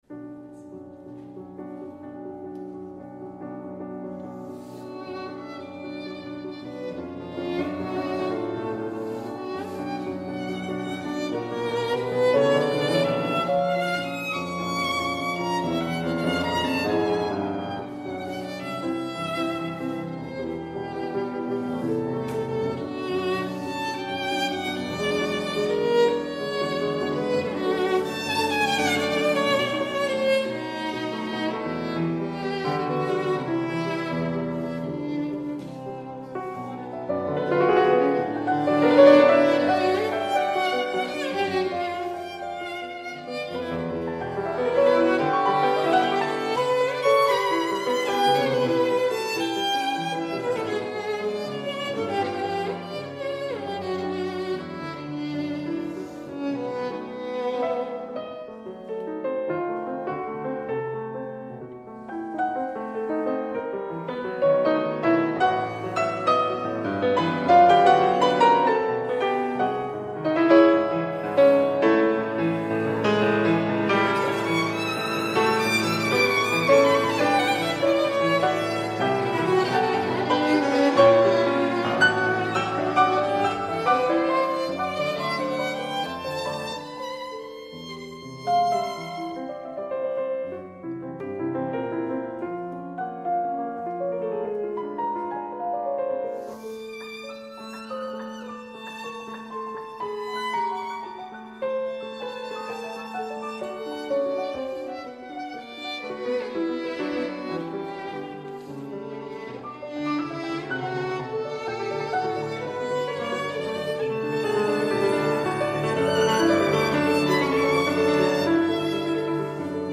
Incontro con la violinista romana classe 2000